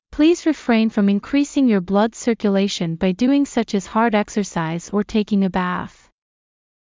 ﾌﾟﾘｰｽﾞ ﾘﾌﾚｲﾝ ﾌﾛﾑ ｲﾝｸﾘｰｼﾞﾝｸﾞ ﾕｱ ﾌﾞﾗｯﾄﾞ ｻｰｷｭﾚｲｼｮﾝ ﾊﾞｲ ﾄﾞｩｰｲﾝｸﾞ ｻｯﾁ ｱｽﾞ ﾊｰﾄﾞ ｴｸｻｻｲｽﾞ ｵｱ ﾃｲｷﾝｸﾞ ｱ ﾊﾞｽ